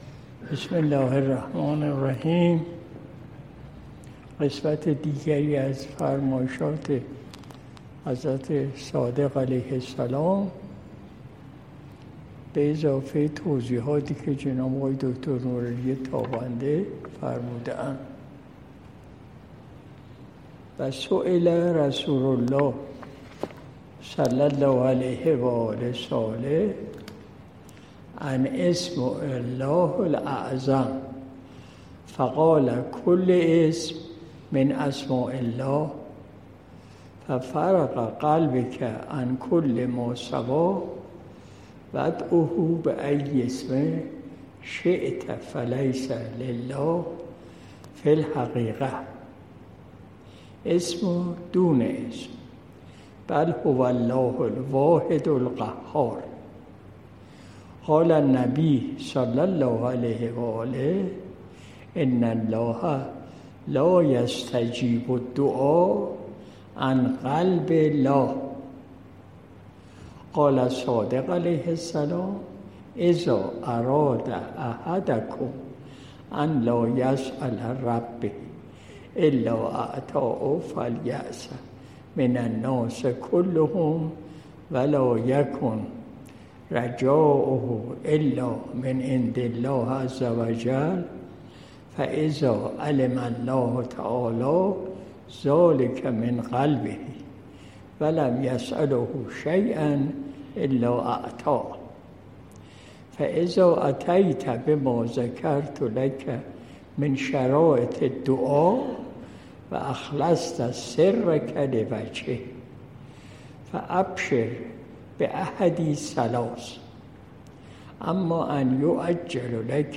مجلس شب دوشنبه ۲۲ مرداد ماه ۱۴۰۲ شمسی